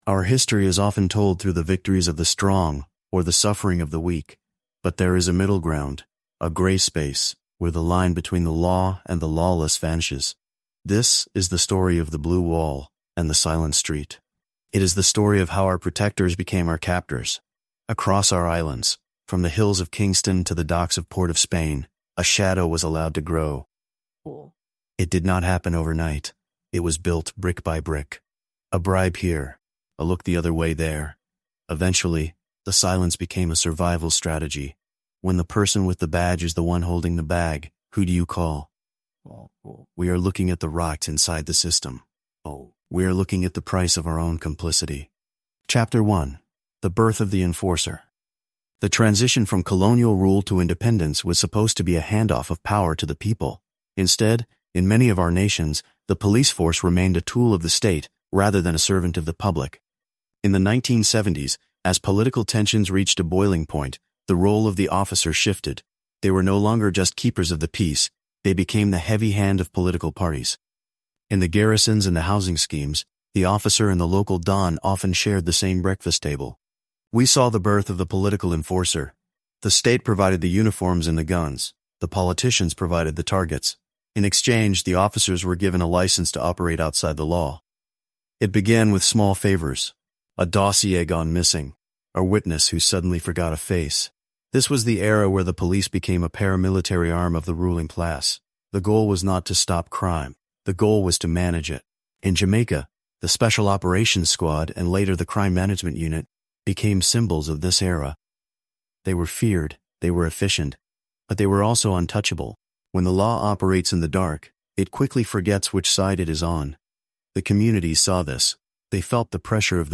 Subscribe to THE HISTORY OF THE CARIBBEAN for deep-dive documentary episodes on the grit, power, and cultural survival of our region.